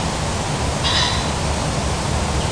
obiknovennij-fazan-phasianus-colchicus.mp3